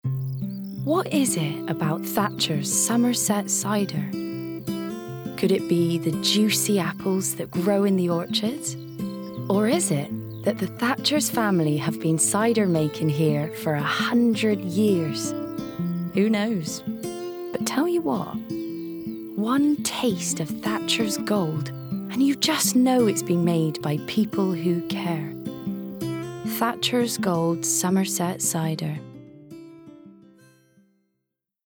Modern/Fresh/Engaging
Thatchers Cider (West Country accent)